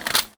R - Foley 121.wav